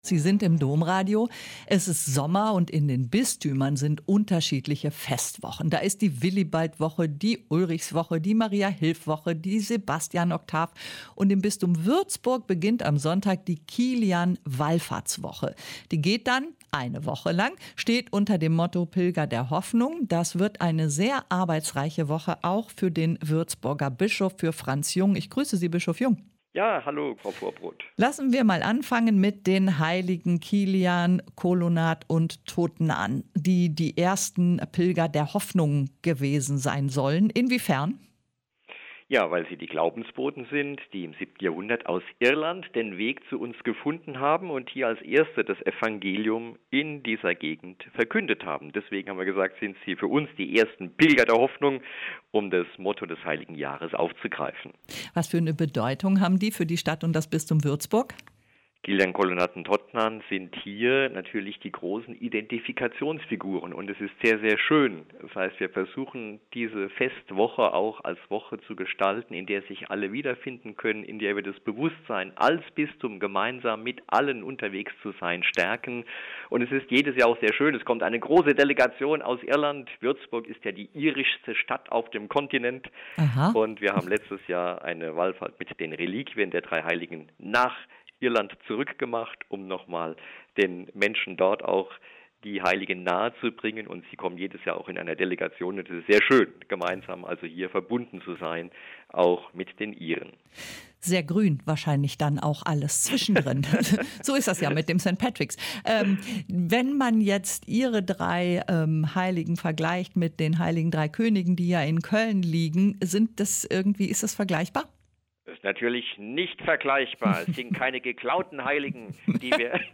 Ein Interview mit Bischof Franz Jung (Bistum Würzburg)